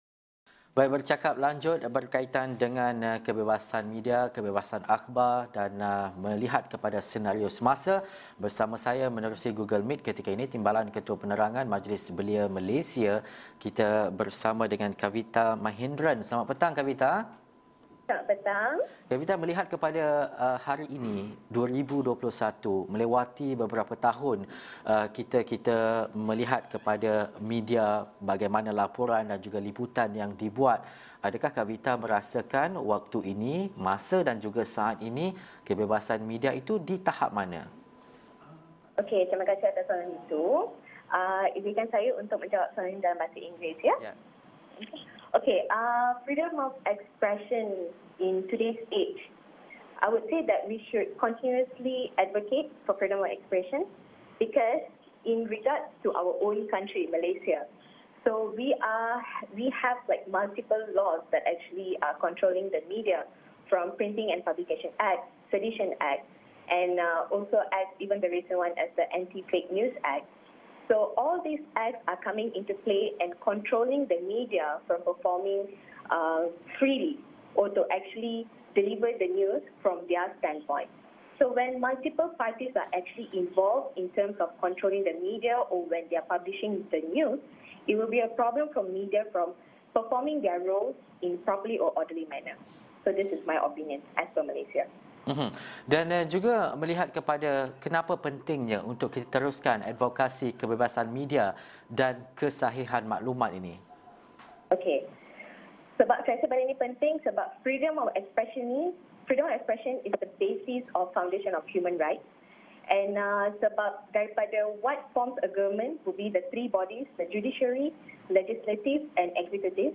Bersama di talian